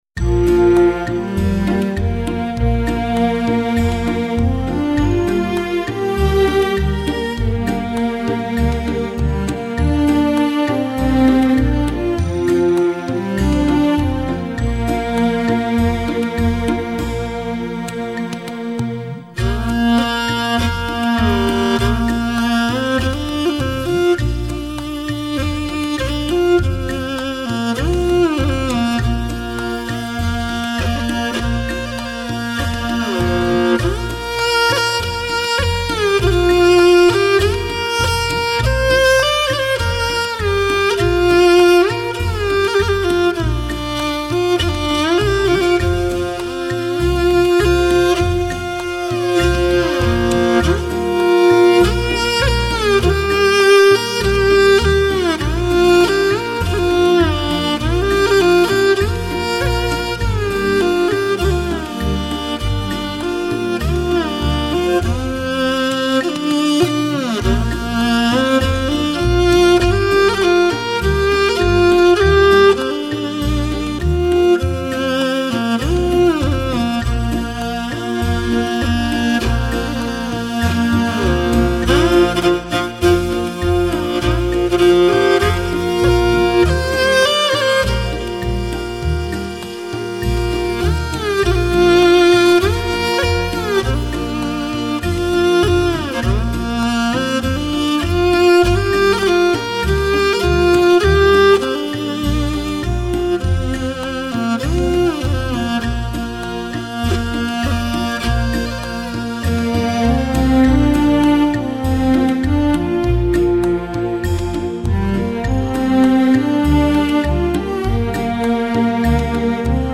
那些平滑的琴弦，扫过草原，捋平一片片苍茫的心事，粗犷是一种质感，草原人也从不缺少传奇与浪漫！